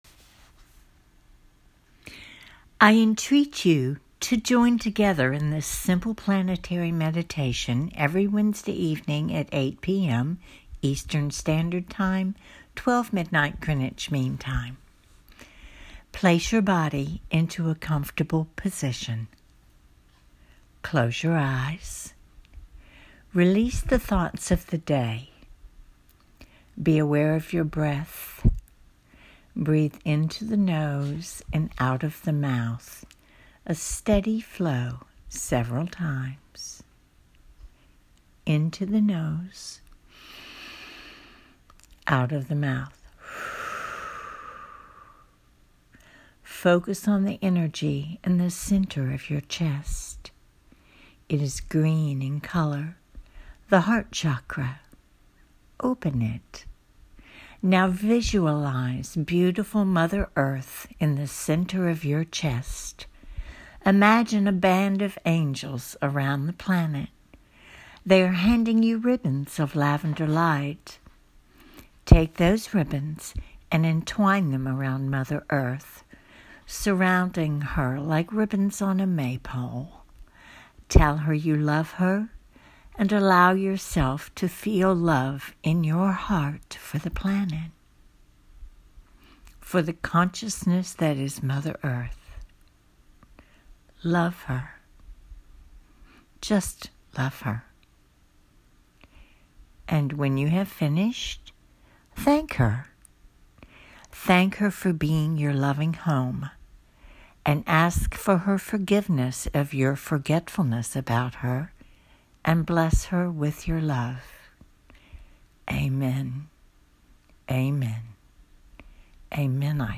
Mother Earth Meditation